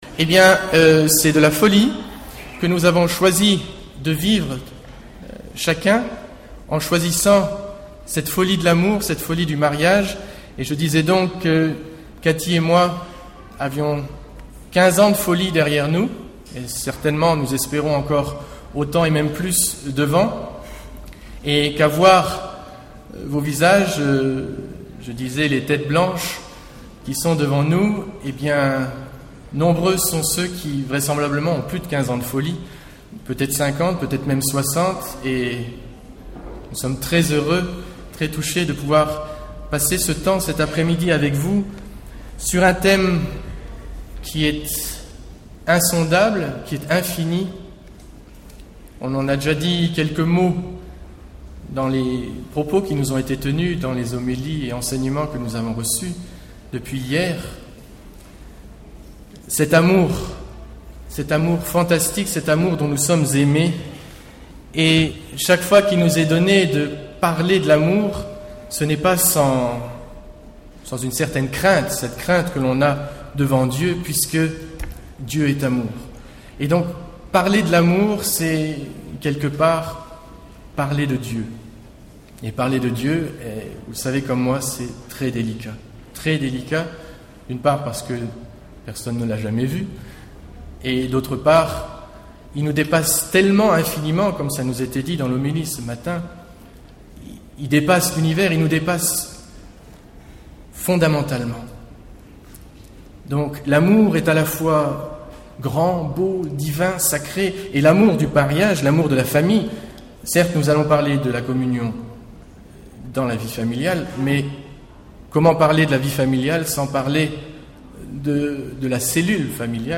Grandir dans la communion Enregistr� en 2001 (Lourdes)Intervenant(s